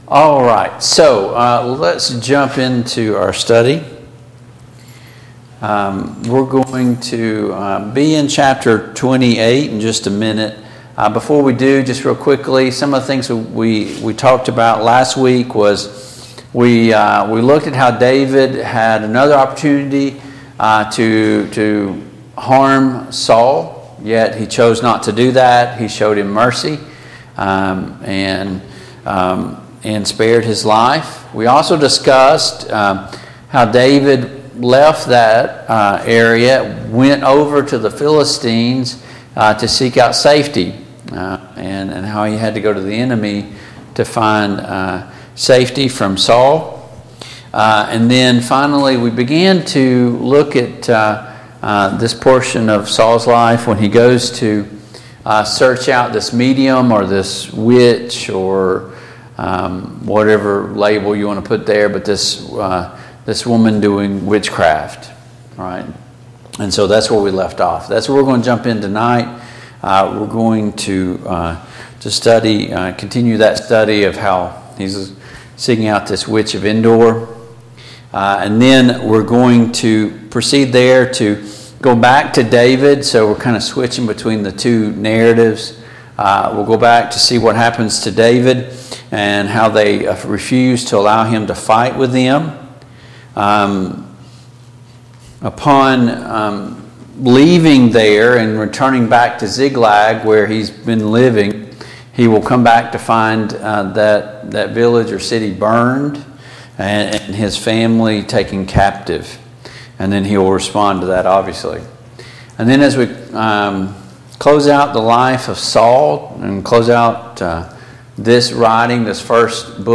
The Kings of Israel Passage: I Samuel 28-30 Service Type: Mid-Week Bible Study Download Files Notes « Misconceptions about the Grace of God 11.